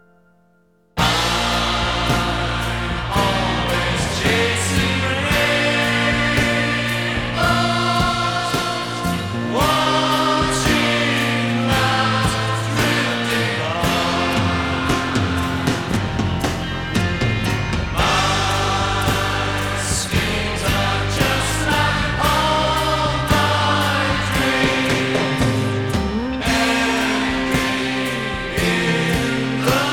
Жанр: Пост-хардкор / Хард-рок / Рок